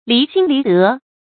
lí xīn lí dé
离心离德发音
成语注音ㄌㄧˊ ㄒㄧㄣ ㄌㄧˊ ㄉㄜˊ